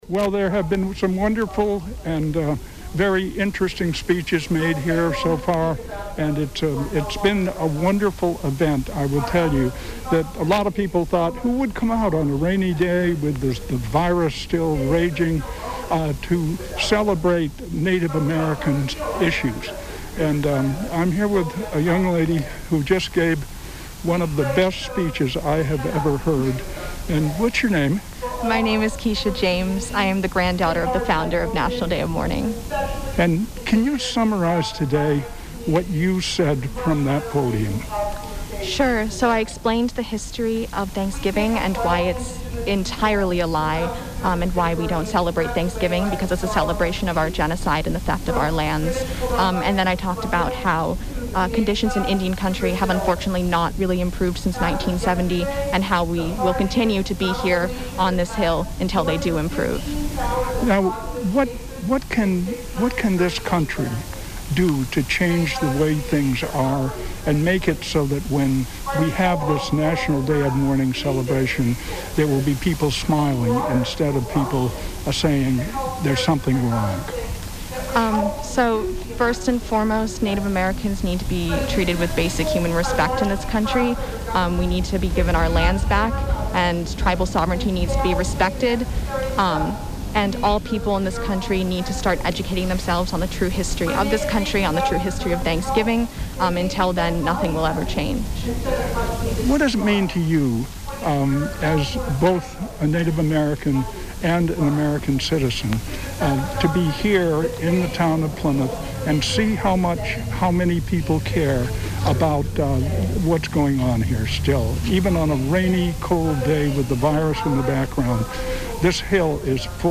Hundreds faced rain and concerns about the COVID-19 Pandemic as Native American activists and their supporters congregated at Coles Hill in Plymouth for the 50th National Day of Mourning.